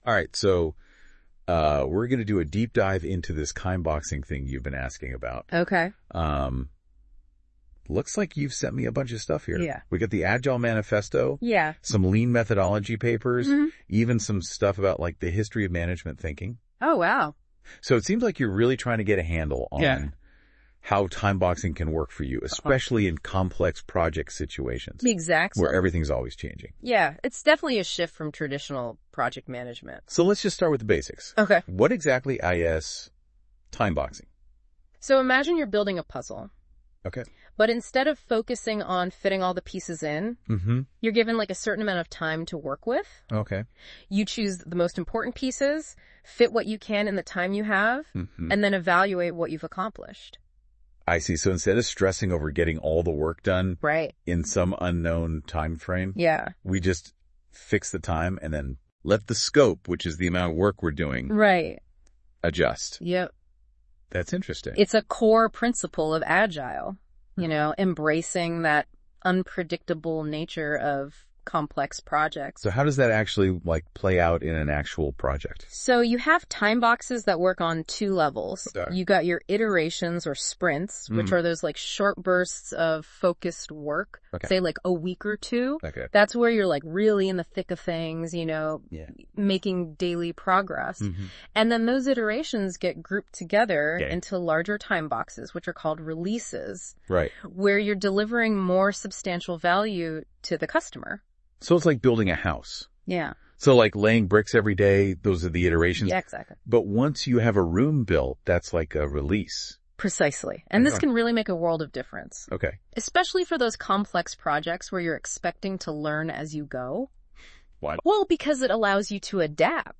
The reason it is important to use timeboxing in controlling complex environments is not always made clear. This podcast is AI-generated based